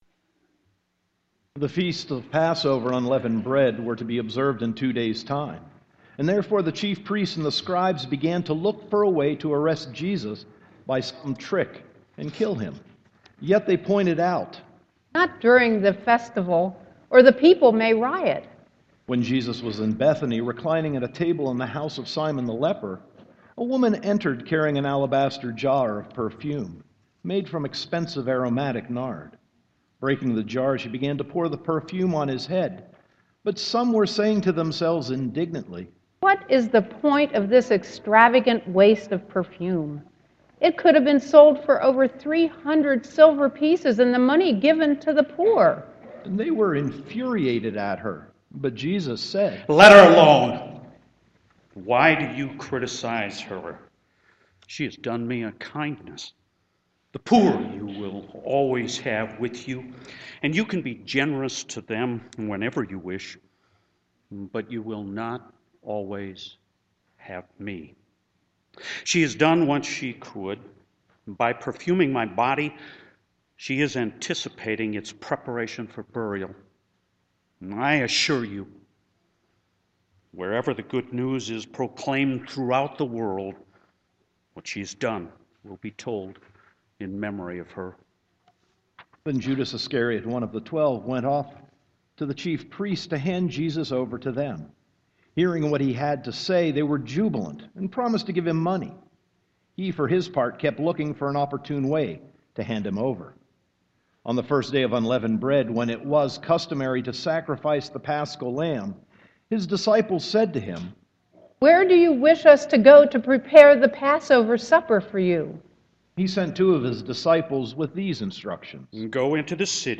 Sermon 3.29.2015